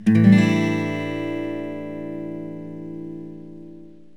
A7sus4.mp3